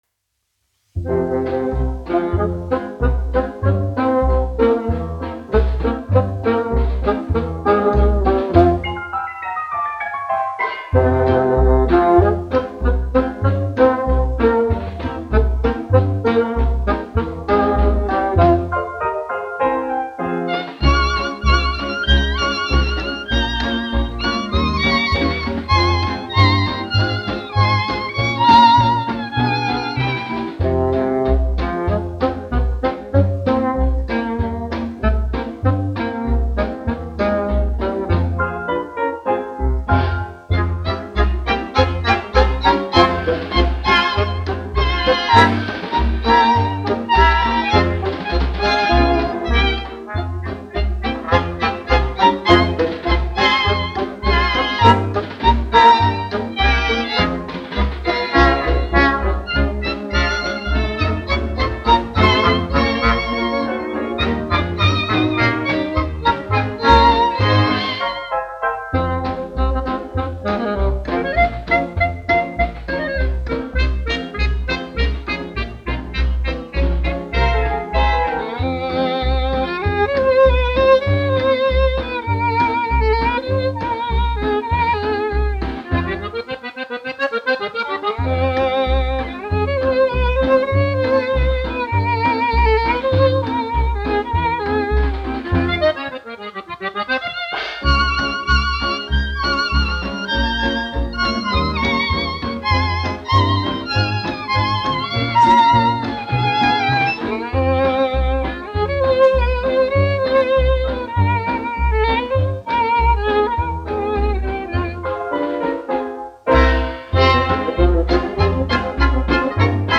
1 skpl. : analogs, 78 apgr/min, mono ; 25 cm
Fokstroti
Skaņuplate
Latvijas vēsturiskie šellaka skaņuplašu ieraksti (Kolekcija)